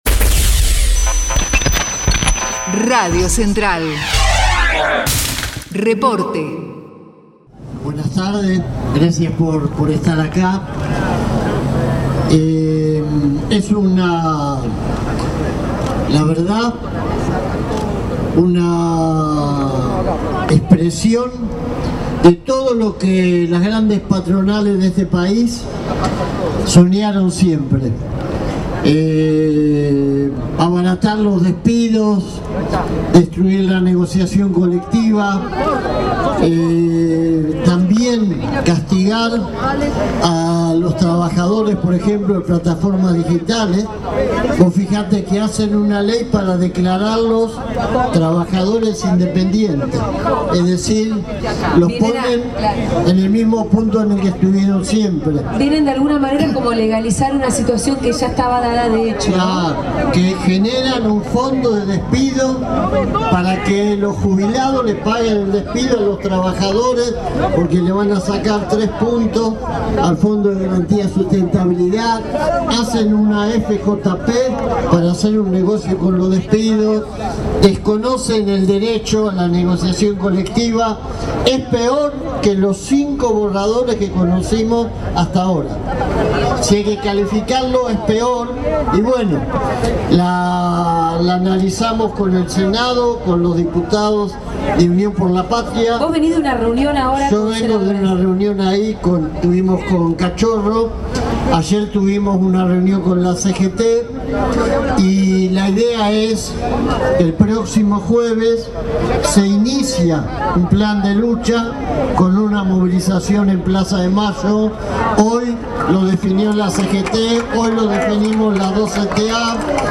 2025_yasky_acto_ddhh.mp3